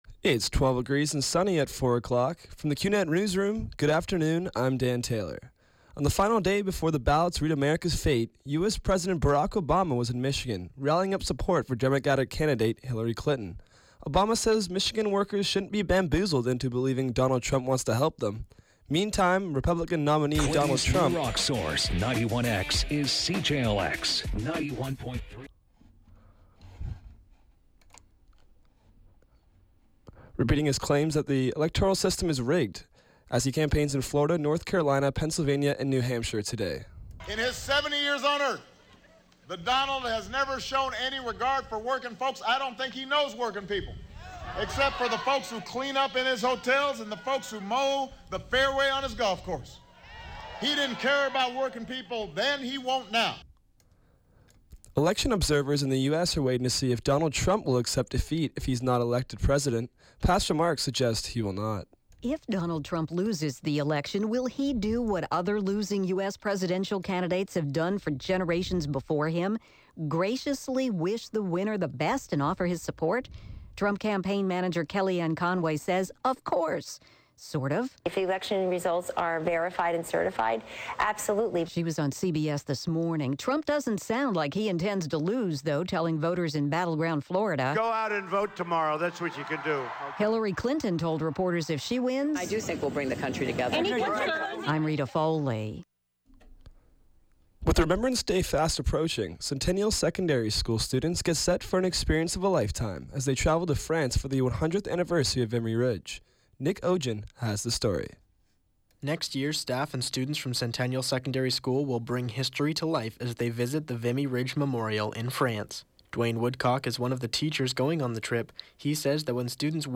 91X FM Newscast – Monday, Nov. 7, 2016, 4 p.m.